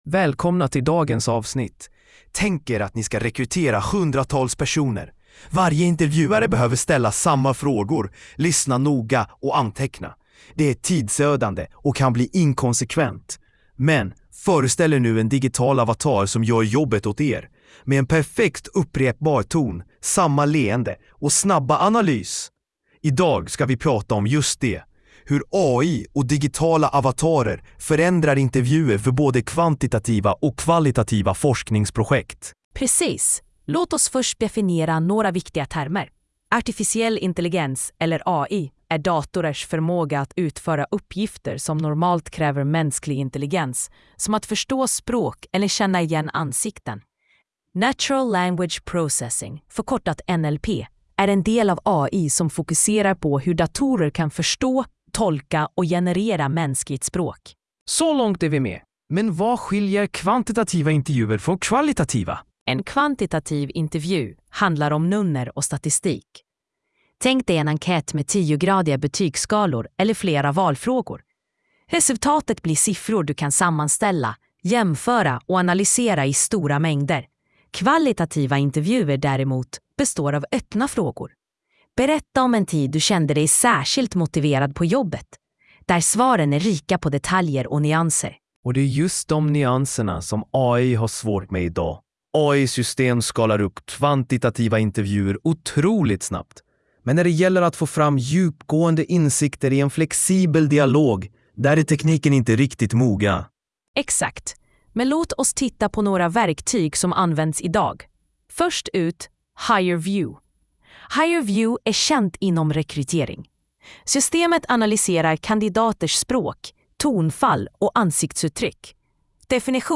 Lyssna på avsnittet genom en AI-genererad podcast: